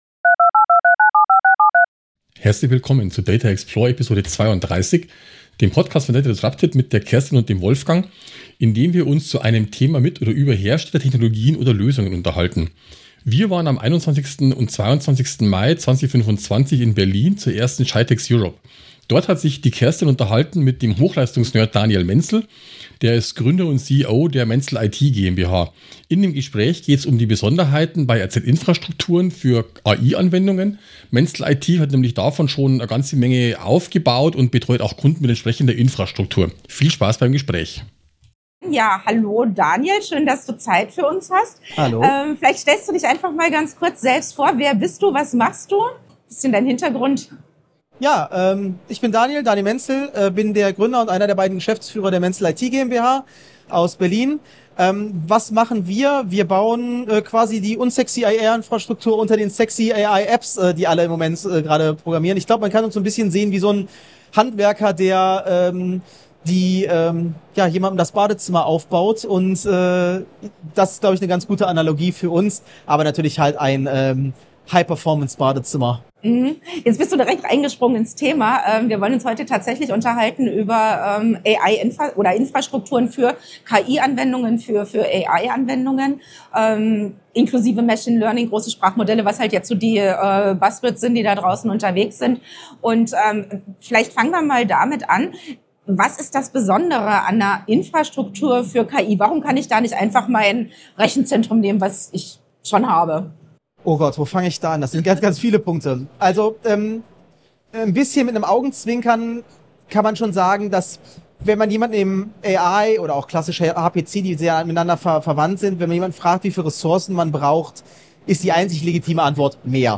Vom 21.-23. Mai 2025 fand in Berlin die erste GITEX Europe statt.